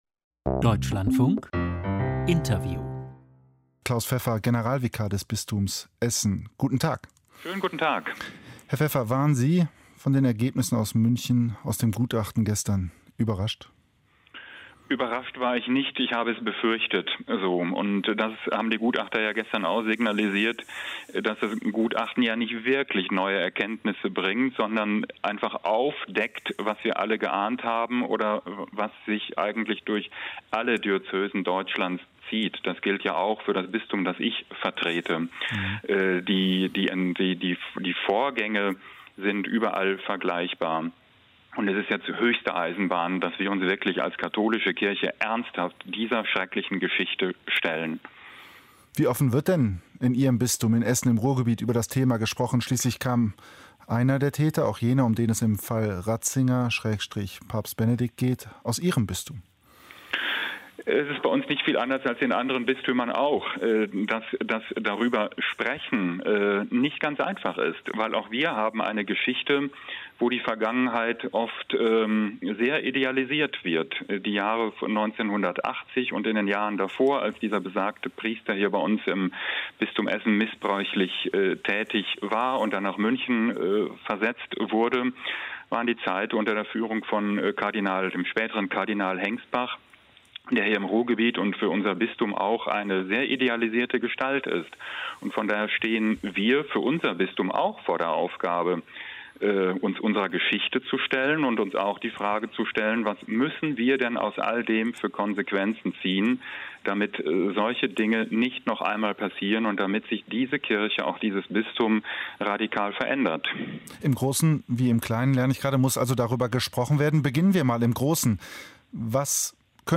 Missbrauchsgutachten, Interview mit Klaus Pfeffer, Generalvikar Bistum Essen